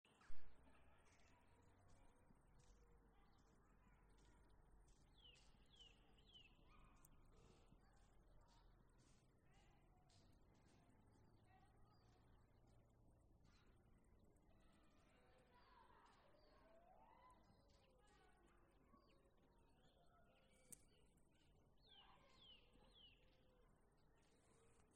Dzilnītis, Sitta europaea
Administratīvā teritorijaViļakas novads
StatussDzied ligzdošanai piemērotā biotopā (D)